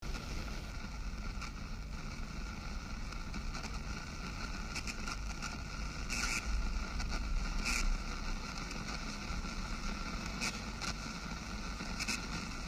noise.m4a